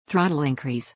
throttleincrease.mp3